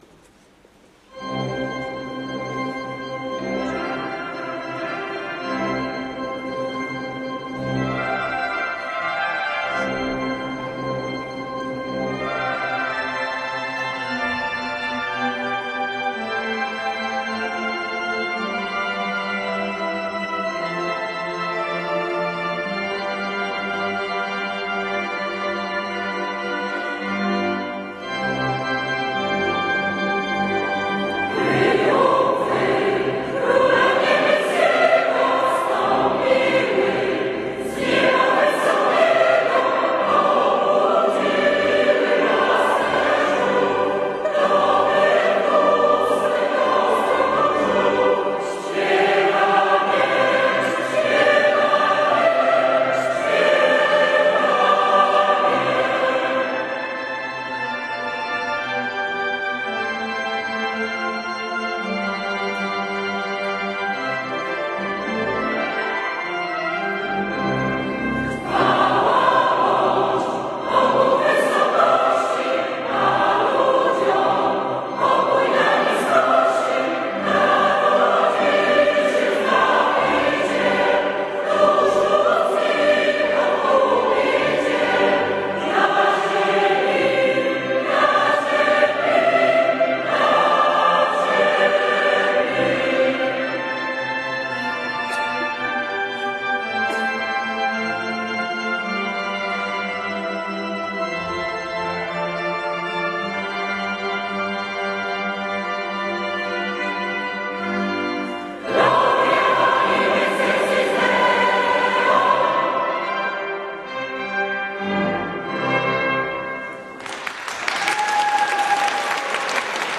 Nagrania wspólnie wykonanych kolęd
Zapraszamy do posłuchania nagrań kolęd wspólnie wykonanych przez 10 chórów parafialnych podczas I Lubelskiego Festiwalu Chórów Parafialnych